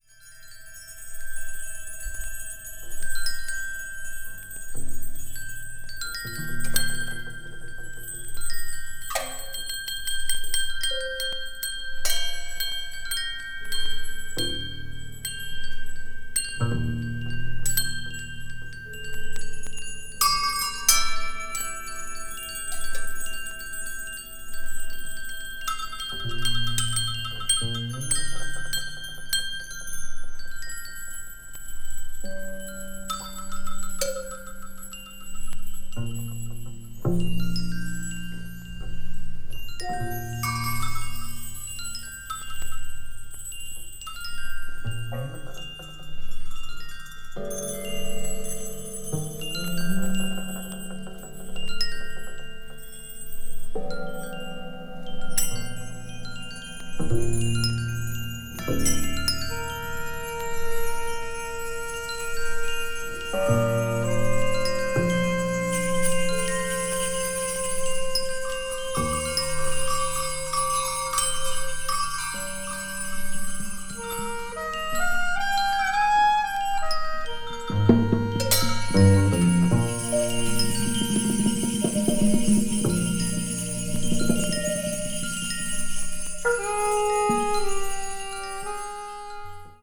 media : EX/EX(わずかにチリノイズが入る箇所あり)
同年に東京の厚生年金会館で行われたコンサートのライヴ・レコーディング音源の一部を編集した作品。